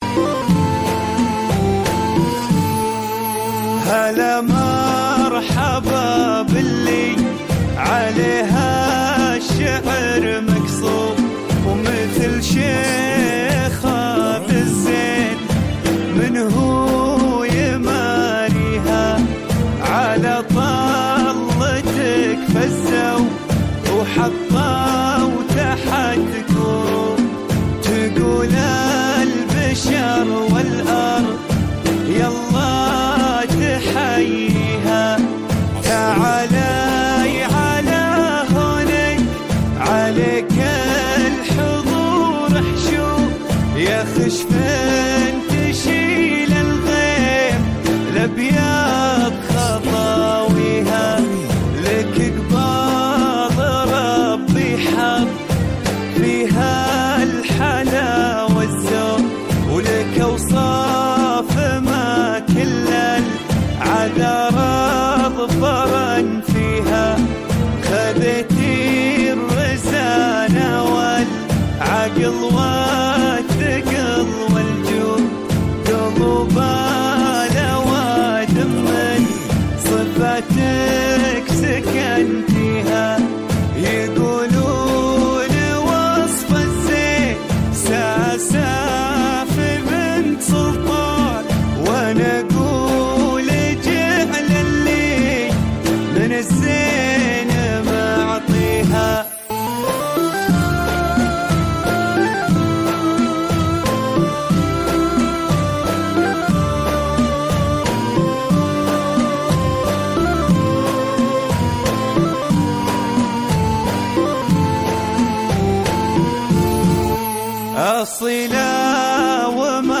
زفة عروس